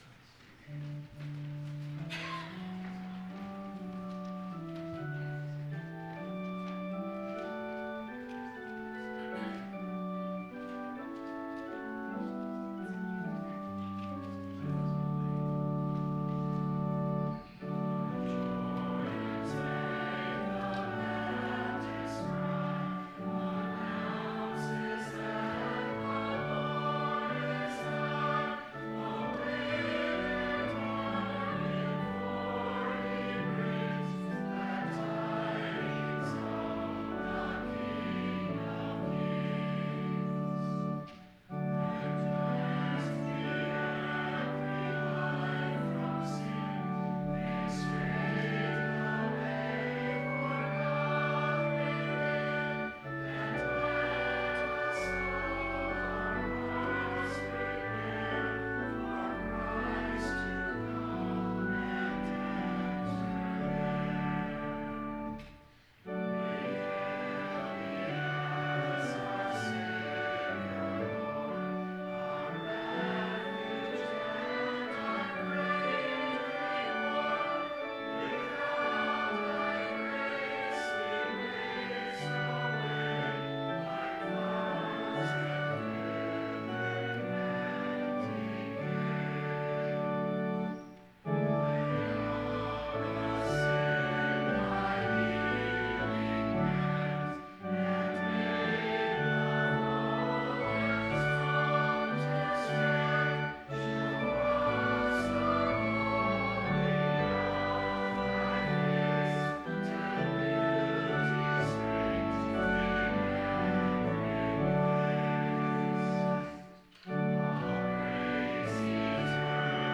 Biblical Text: Matthew 3:1-12 Full Sermon Draft